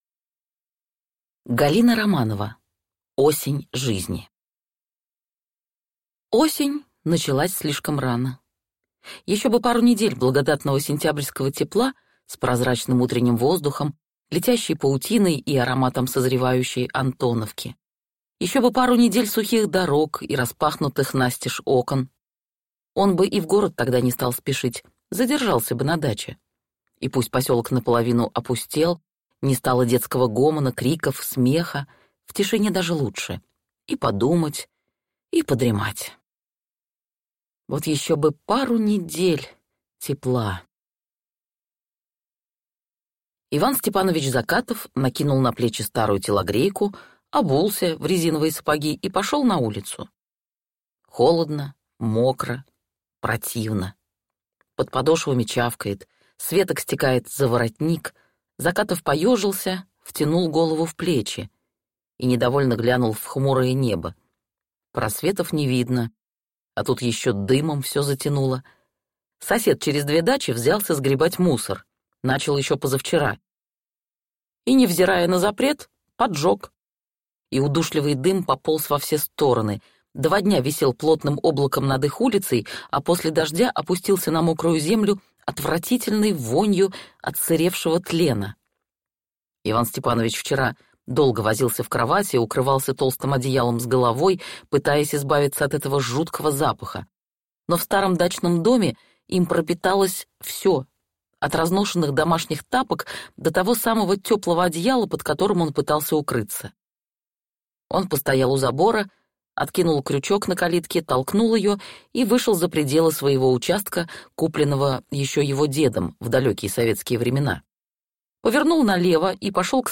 Аудиокнига Осень жизни | Библиотека аудиокниг